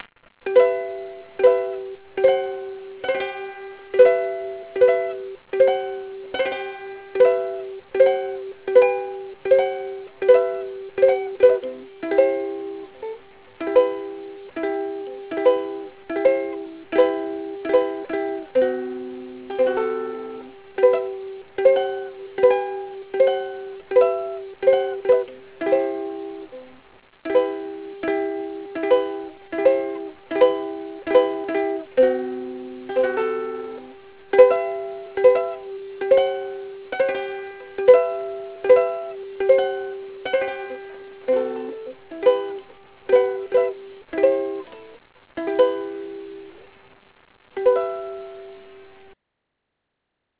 かの有名な「さくらさくら*1」を ウクレレ用に編曲してみました。
コードストローク編 (49s)